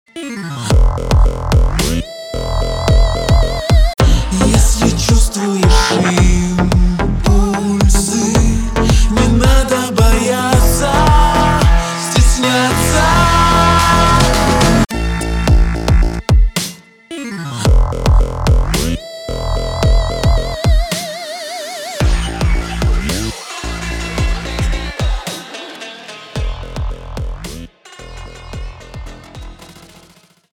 • Качество: 320, Stereo
remix
басы
цикличные